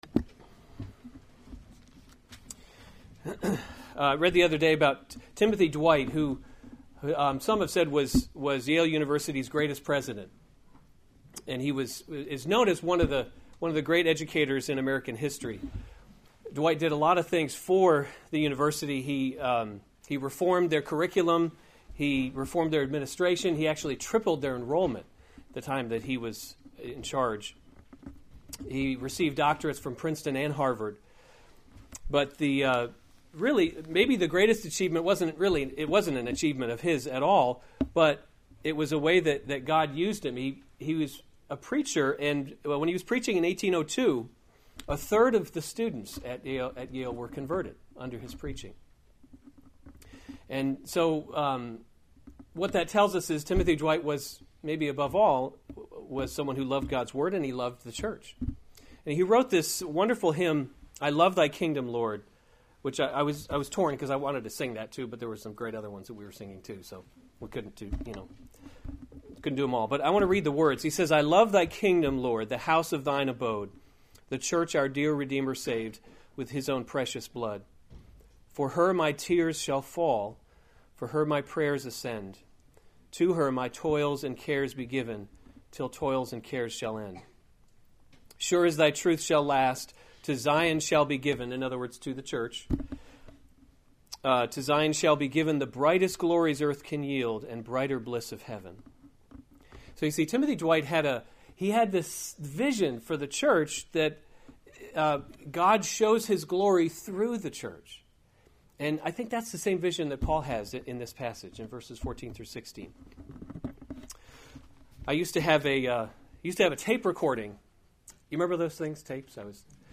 April 1, 2017 1 Timothy – Leading by Example series Weekly Sunday Service Save/Download this sermon 1 Timothy 3:14-16 Other sermons from 1 Timothy The Mystery of Godliness 14 I hope […]